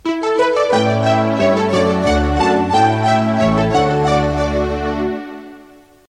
Sintonia curta de l'emissora